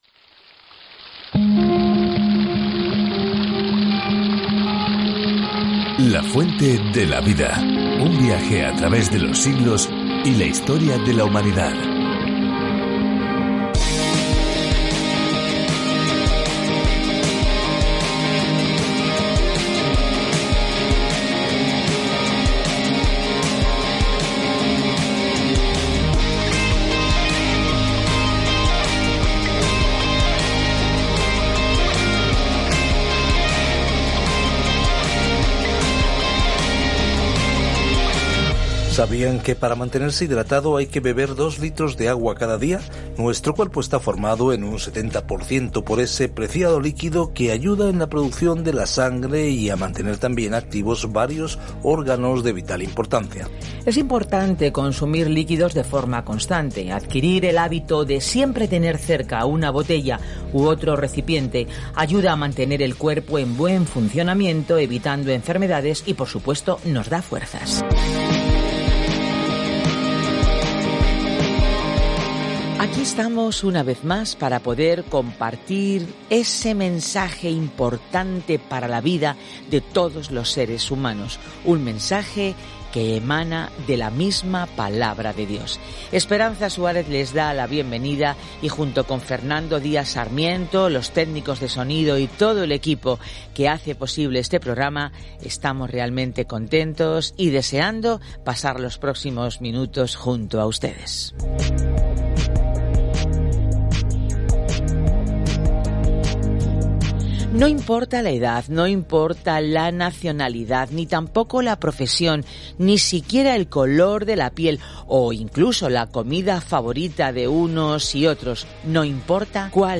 Escritura MATEO 24:14-32 Día 36 Iniciar plan Día 38 Acerca de este Plan Mateo demuestra a los lectores judíos las buenas nuevas de que Jesús es su Mesías al mostrar cómo su vida y ministerio cumplieron la profecía del Antiguo Testamento. Viaje diariamente a través de Mateo mientras escucha el estudio de audio y lee versículos seleccionados de la palabra de Dios.